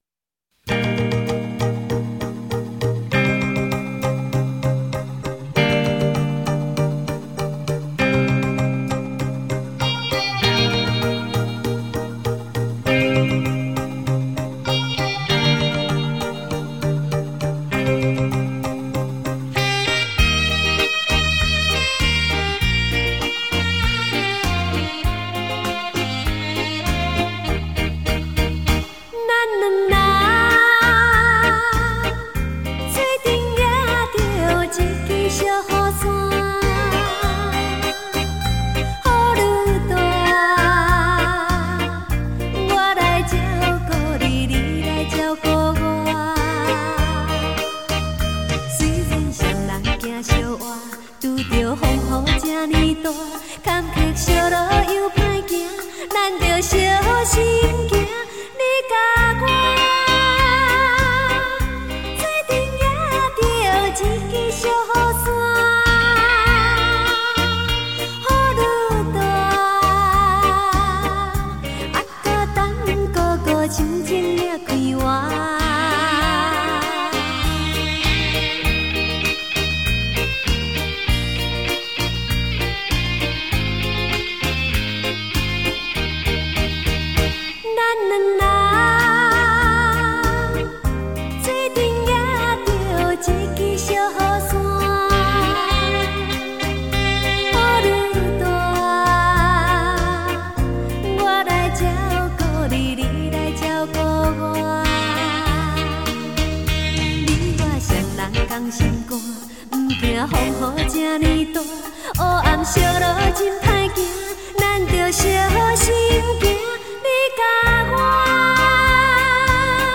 舞厅规格
伦巴歌唱版
将自己投入感性的歌声中